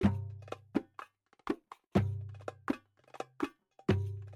conga timbo.wav